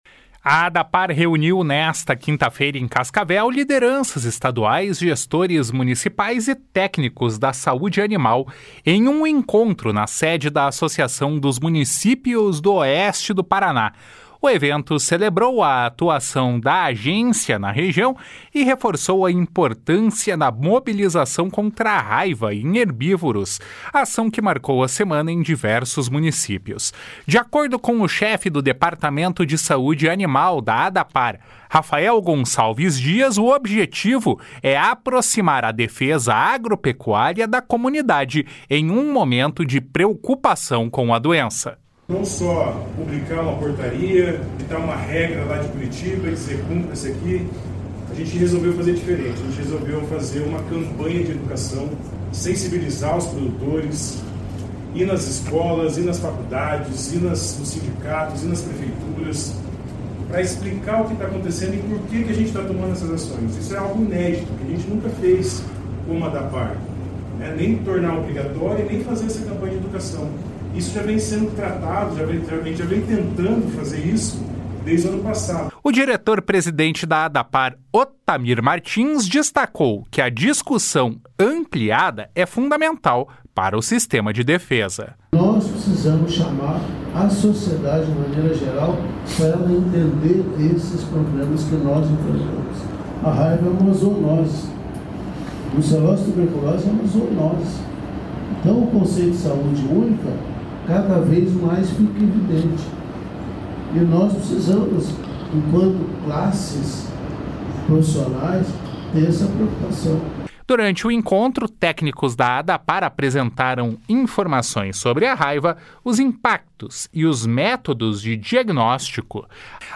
O diretor-presidente da Adapar, Otamir Cesar Martins, destacou que a discussão ampliada é fundamental para o sistema de defesa.